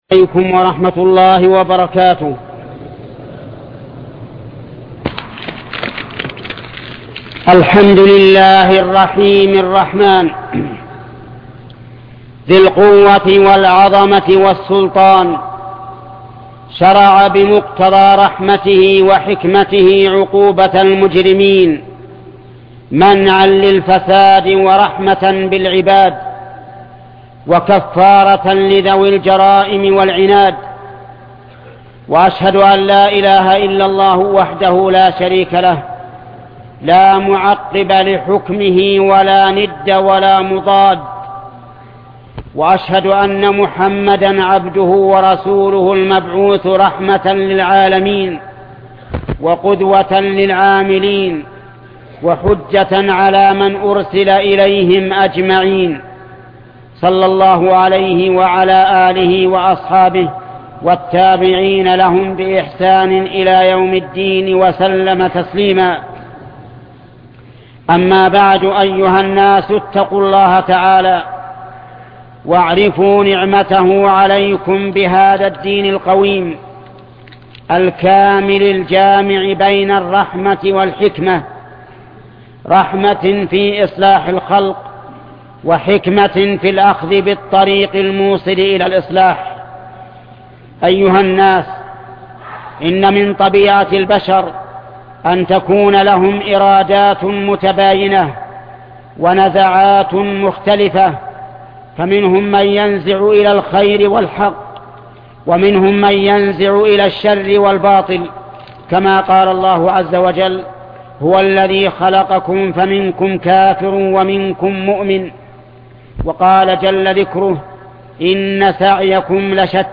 خطبة عقوبة المجرمين الشيخ محمد بن صالح العثيمين